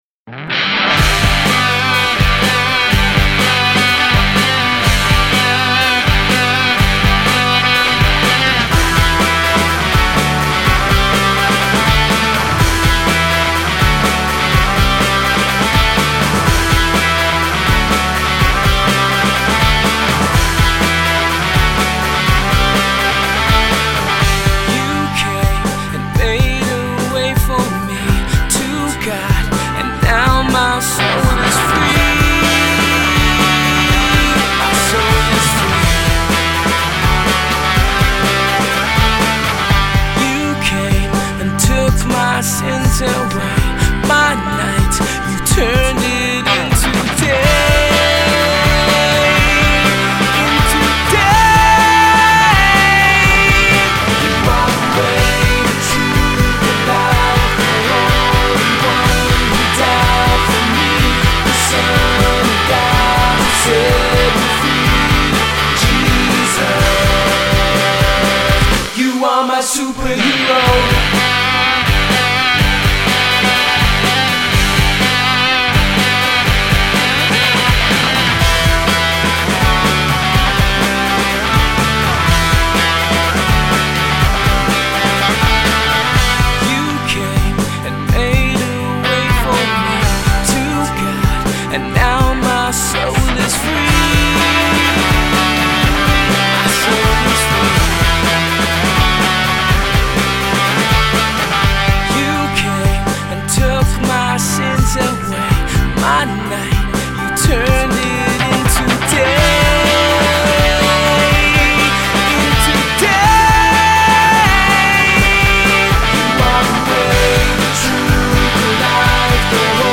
one of which is Nigeria’s very own prime indie band